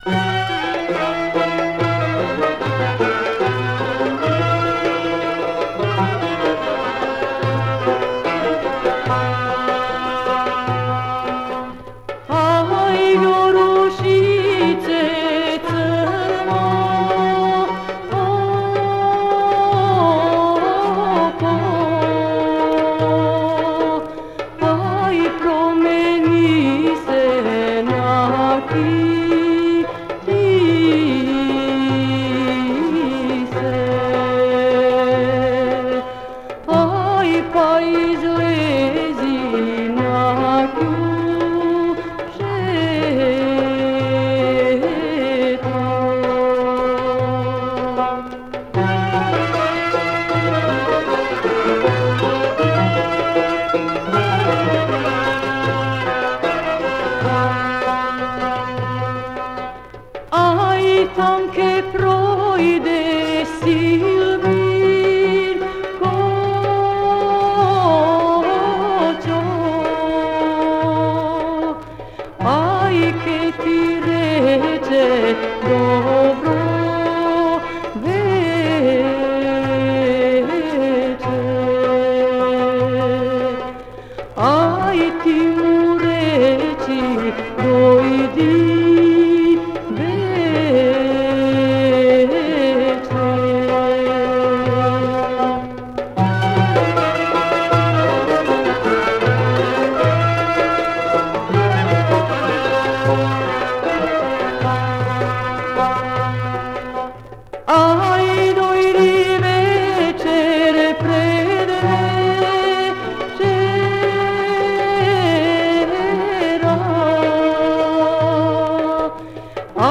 Genre: National Folk